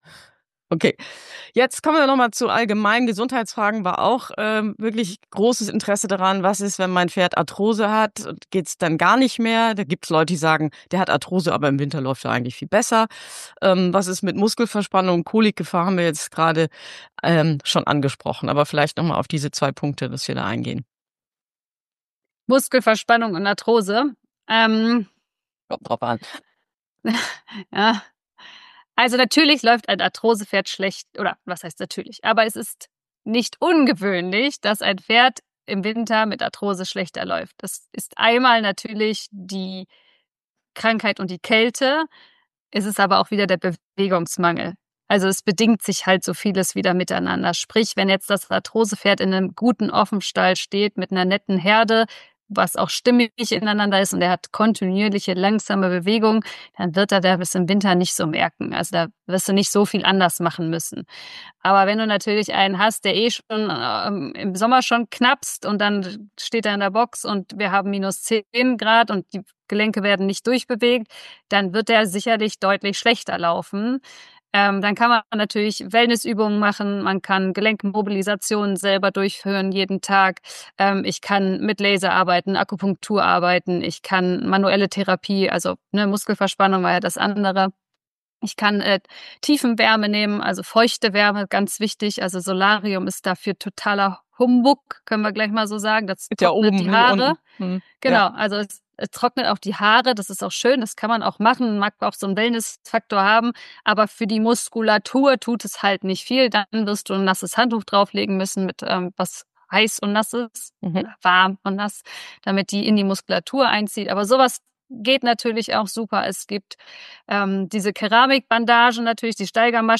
Pferde im Winter - Teil 2 (Gast-Interview) ~ Kernkompetenz Pferd